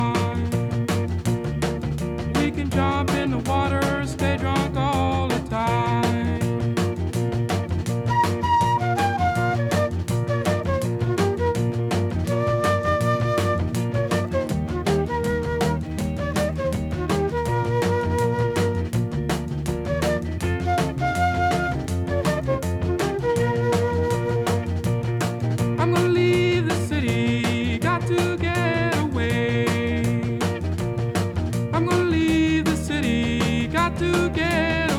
# Blues